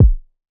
Kick (7).wav